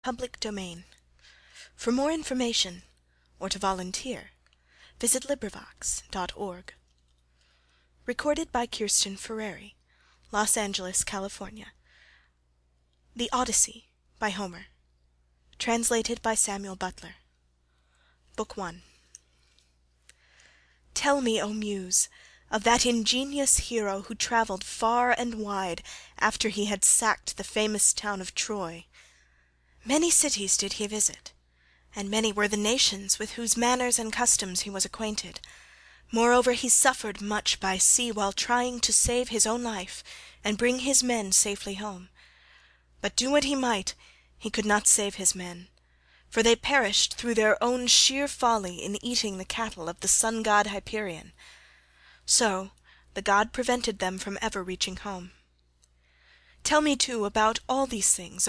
کتاب صوتی انگلیسی The Odyssey | مرجع دانلود زبان